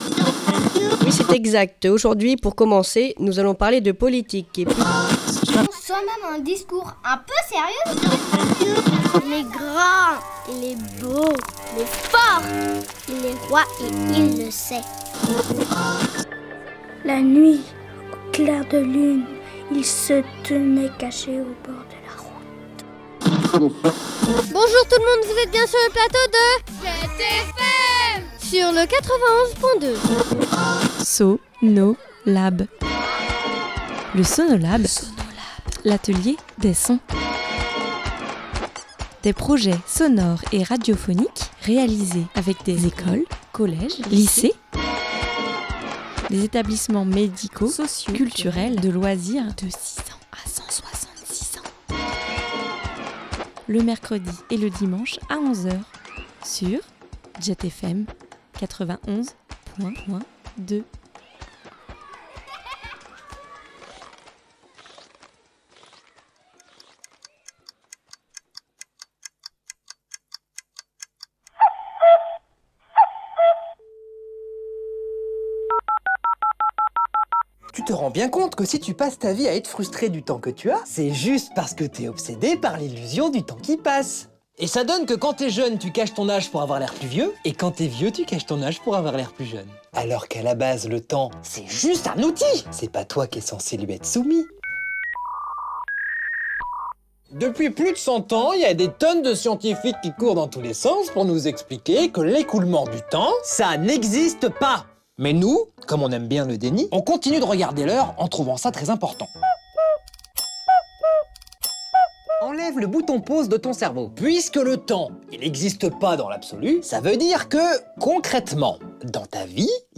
Ils se sont initiés à la prise de sons ( interviews, micros-trottoirs, bruitages ) au montage et à l’écriture radiophonique (journalistique et fictionnelle).
– des chroniques ( cinéma, philosophique) – Une fiction radiophonique – deux micro-trottoir – un billet d’humeur – un medley musical